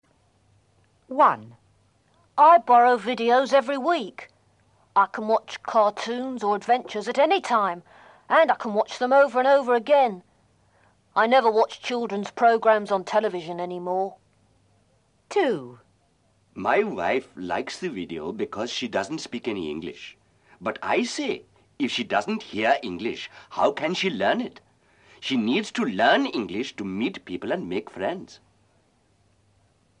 Listen to This比较大的一个特点是听力材料包含有多种口音。
感受一下第一册材料中的多种口音：